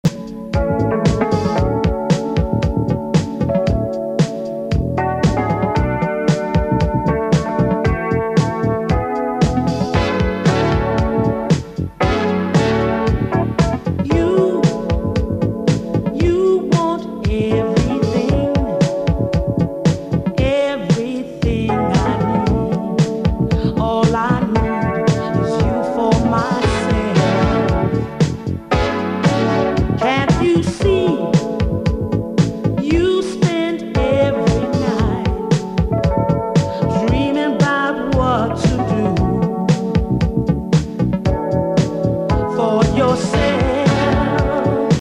of an established soul and jazz tune.
recorded in the 70s with live bass and full
rhythm section with horns.